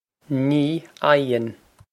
Pronunciation for how to say
Nee ie-on.
This is an approximate phonetic pronunciation of the phrase.